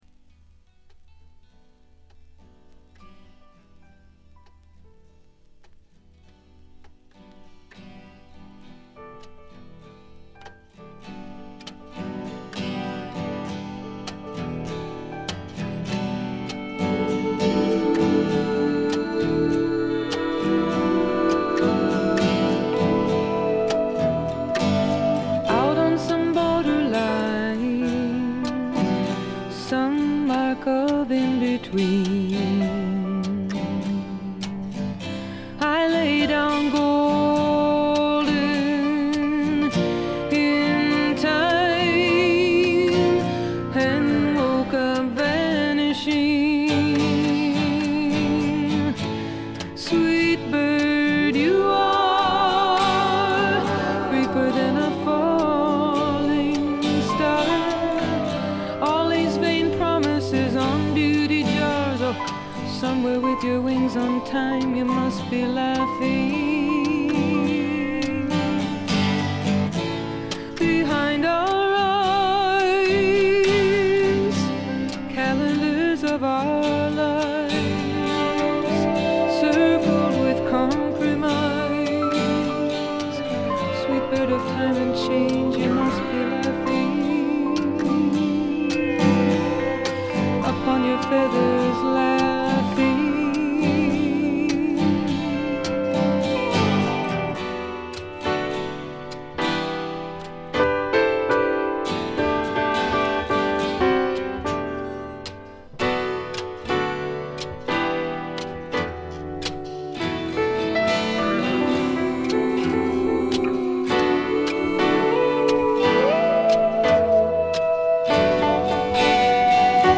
ディスク:部分試聴ですがほとんどノイズ感無し。極めて良好に鑑賞できると思います。実際の音源を参考にしてください。
ここからが本格的なジャズ／フュージョン路線ということでフォーキーぽさは完全になくなりました。
女性シンガーソングライター名作。
試聴曲は現品からの取り込み音源です。